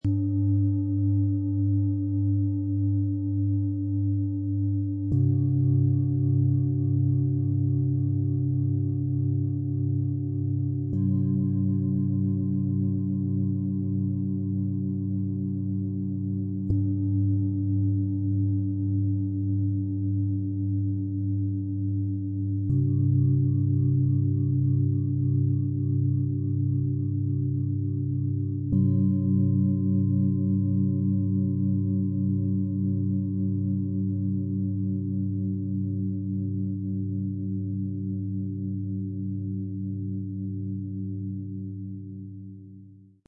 Klangmassage-Set Stabilität & Halt - beruhigend, stärkend, ausgleichend - aus 3 Klangschalen, Ø 17,8 - 24,5 cm, 2,99 kg
Ein Klangbogen vom tiefen, beruhigenden Bauchklang bis zur klaren Herznote.
Becken- und Bauchschale • Ø 24,5 cm • 1406 g. Klingt tief und sonor. Der Klang trägt und beruhigt – wie ein festes Klangfundament, das Sicherheit schenkt.
Bauch- und Herzschale • Ø 20 cm • 928 g. Klingt voll und harmonisch.
Herz- bis Kopfschale • Ø 17,8 cm • 654 g. Klingt klar und singend.
Mit dem beiliegenden Klöppel erklingen die Schalen harmonisch und tief.